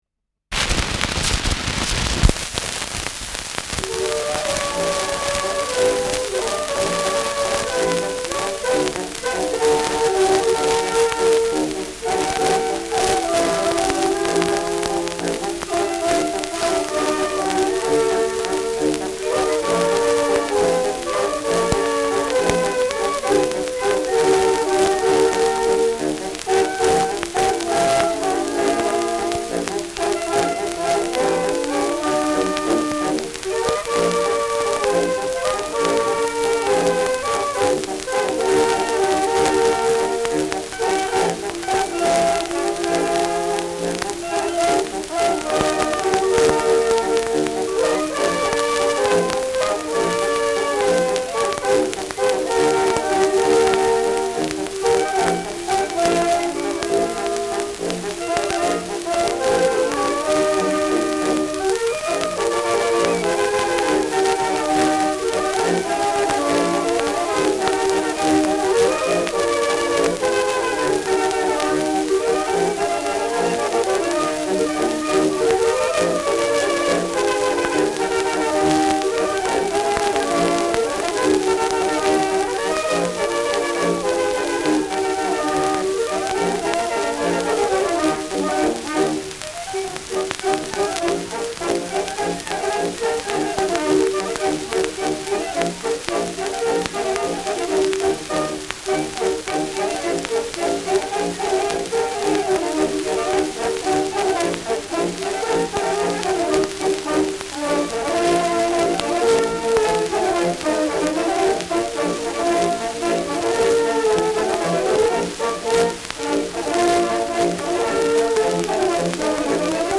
Schellackplatte
Stark abgespielt : Starkes Grundrauschen : Durchgehend leichtes Knacken
Stadtkapelle Fürth (Interpretation)
Der Titel beginnt mit dem bekannten „Nach Hause gehn wir nicht“, nach einem Ländler-Zwischenspiel folgt eine (unter verschiedenen Namen bekannte) Schottisch-Melodie und zuletzt ein Dreher – in einem Titel wird sozusagen eine komplette „Schlusstour“ gespielt.